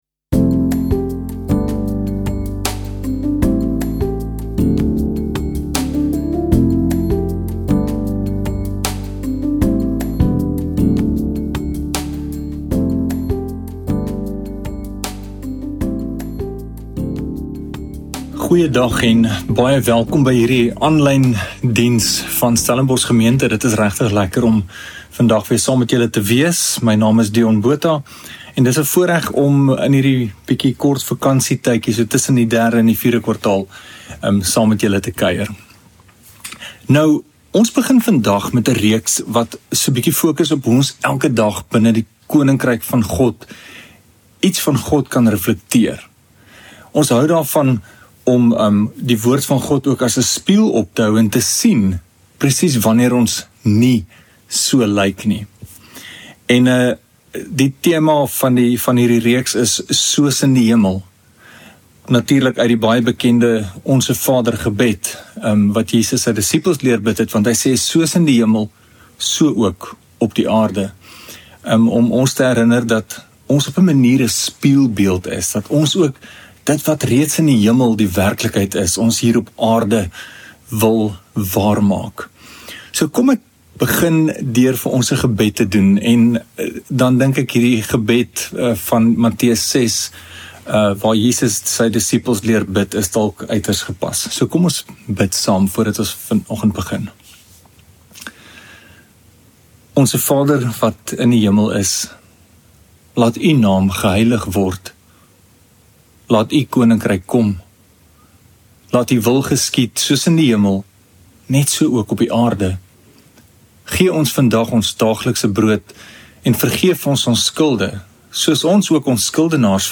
Stellenbosch Gemeente Preke